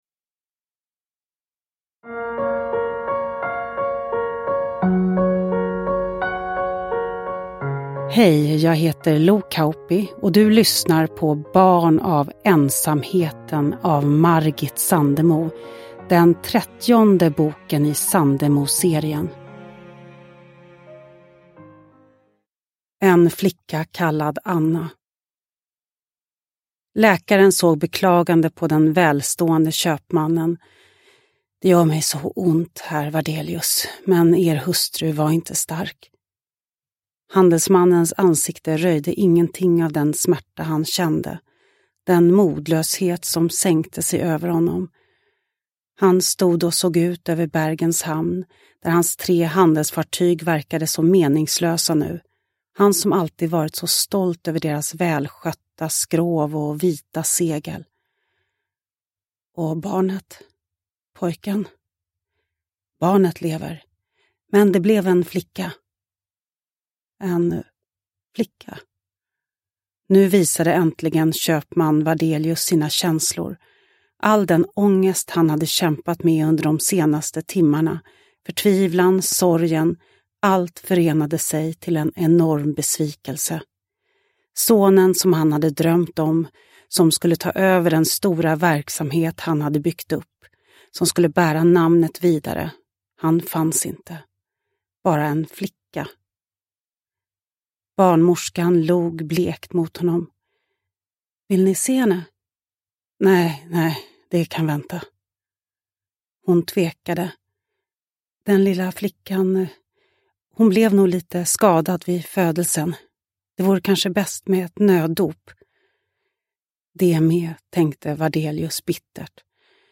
Barn av ensamheten – Ljudbok – Laddas ner
Uppläsare: Lo Kauppi